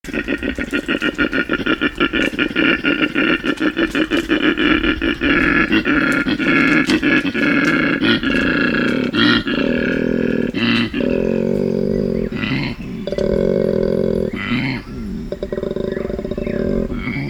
Audio recorder array surveys 2023 – In partnership with Mid Coast Council and the Department of Primary Industries we will set up an array of 25-30 audio recorders to capture the dulcet sound of male koala breeding bellows and yellow-bellied glider mating calls (both attached below).
koala-mp-3-dade9a.mp3